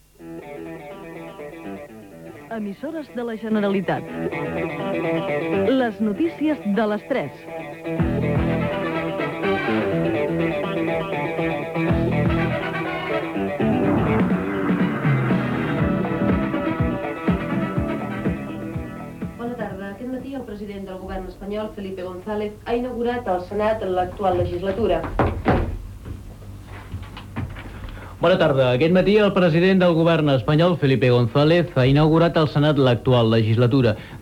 Identificació del programa, primera informació sobre l'inici de la tercera legislatura espanyola (amb una errada tècnica i el locutor que arriba tard fets que provoquen una repetició del titular)
Informatiu
FM